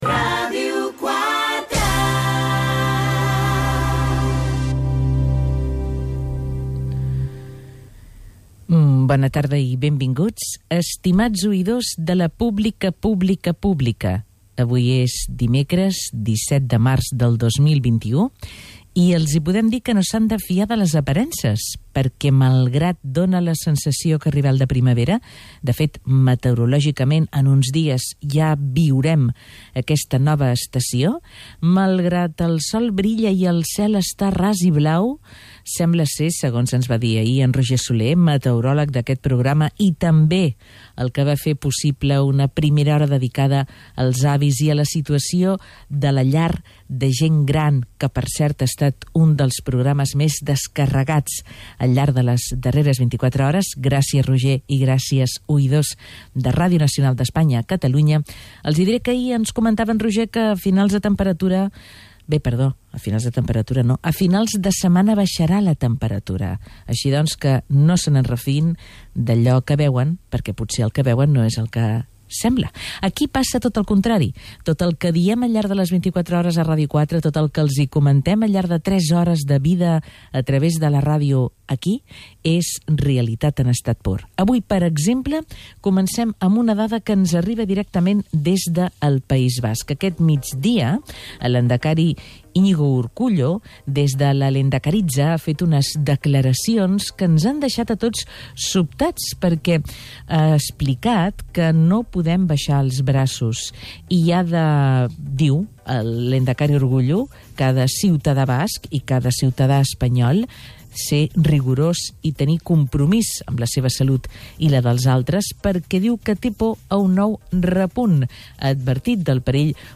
El pasado 17 de Marzo la APVPBP participó en el programa "De boca a orella" de Radio 4
En primer lugar lugar el programa da voz a una trabajadora de estos nuevos modelos de negocio que han aparecido recientemente dentro del colectivo